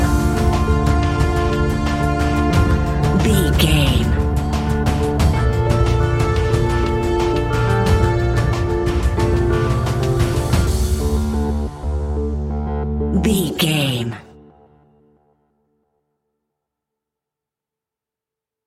royalty free music
In-crescendo
Thriller
Aeolian/Minor
D
ominous
dark
suspense
haunting
eerie
synthesiser
drums
percussion
electronic music